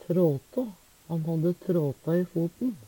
tråtå - Numedalsmål (en-US)